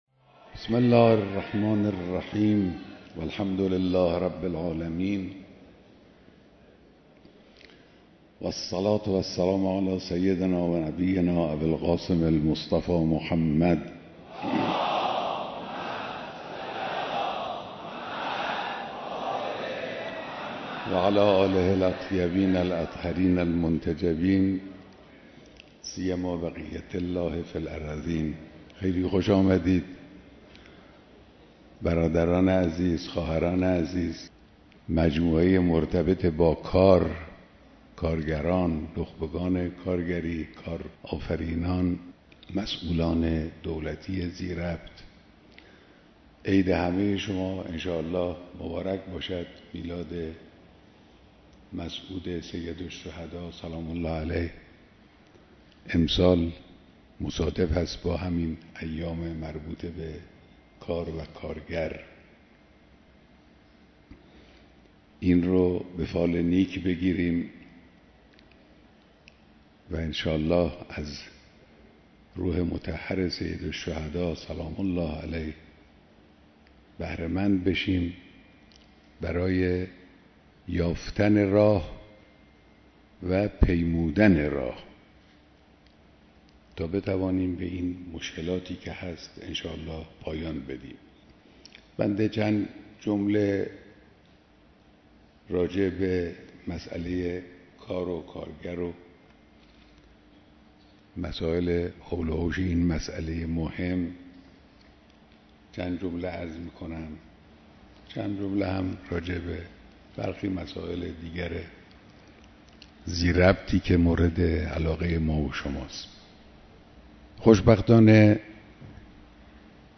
دیدار جمعی از کارگران سراسر کشور و مسئولان حوزه کارگری
بیانات در دیدار کارگران به مناسبت روز کارگر